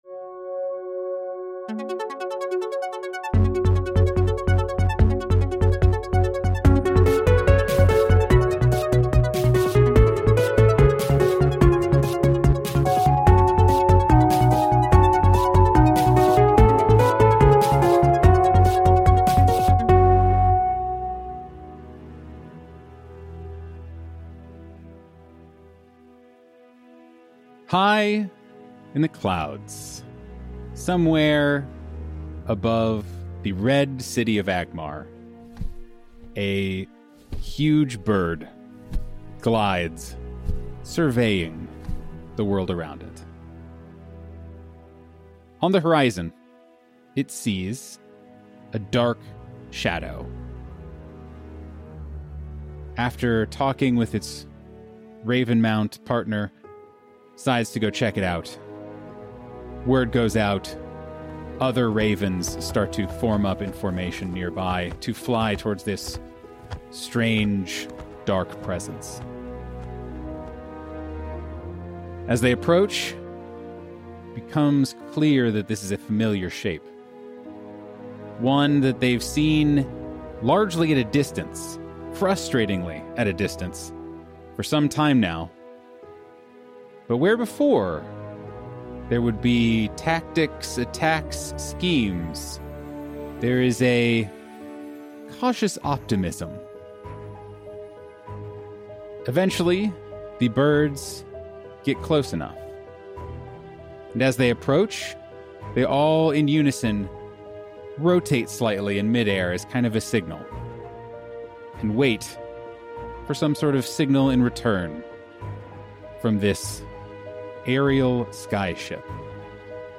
25 Mar 2025 10:00:00 +0000 Welcome to another episode of Reckless A-Talk, our TTRPG interview show where we sit down with some of our favorite writers, players, GMs, and streamers to get to know a little bit more about what makes them who they are.